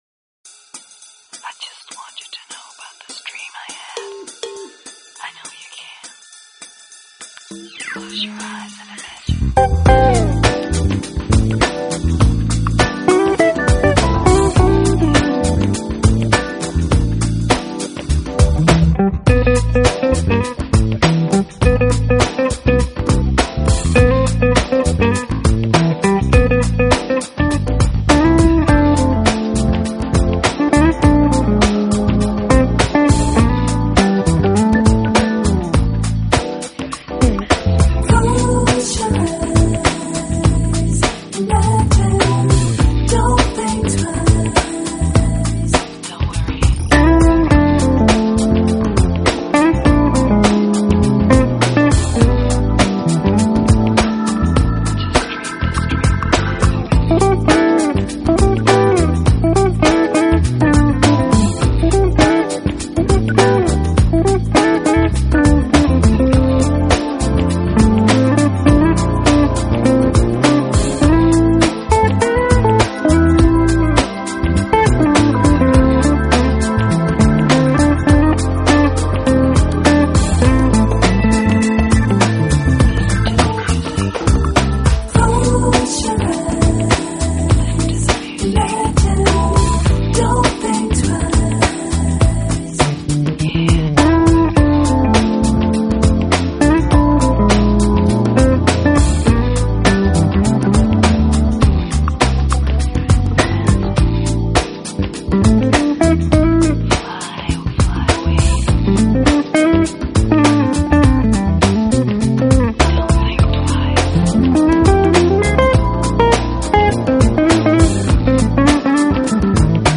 Smooth Jazz/Lounge
坚持原创所有曲目带有独特的smooth jazz/lounge
并且融合了pop/funk/latin的节奏。